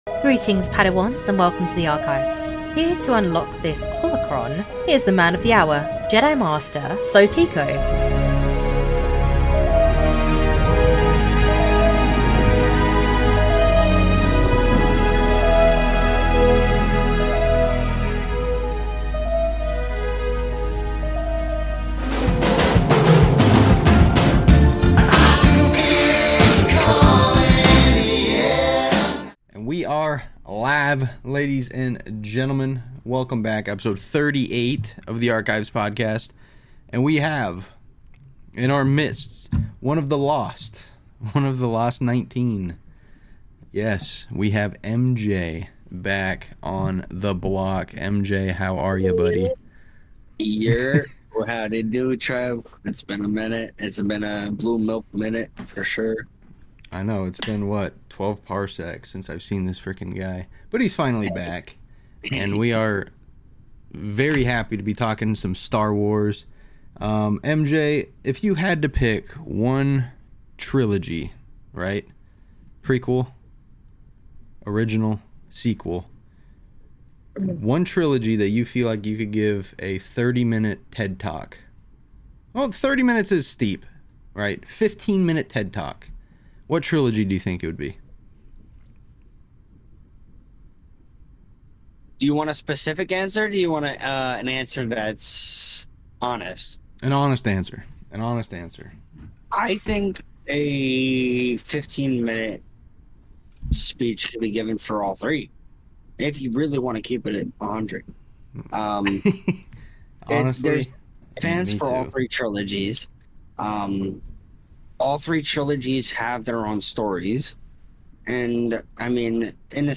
Episode 38 of Star Wars: The Archives Podcast dives into a fiery debate over Kylo Ren, one of the sequel trilogy's most controversial characters. The discussion explores the complexities of Kylo's redemption arc, his inner conflict between the dark and light sides of the Force, and his ultimate transformation back into Ben Solo. Some panelists champion Kylo's nuanced portrayal, highlighting his struggle as a powerful addition to the Star Wars legacy, while others criticize the execution of his arc, labeling it rushed and inconsistent.